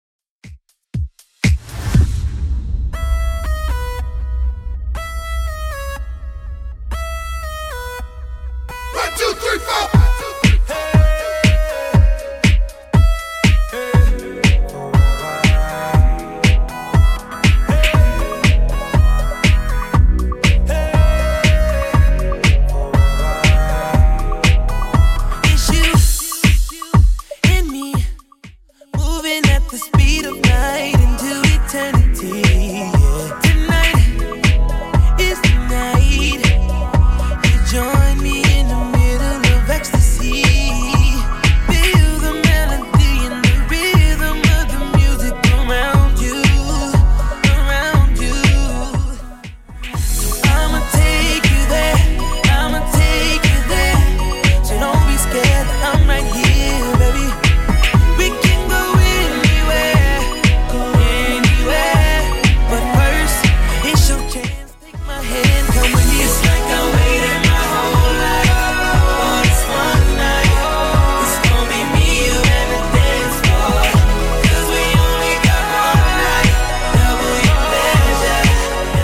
Genre: 90's
BPM: 122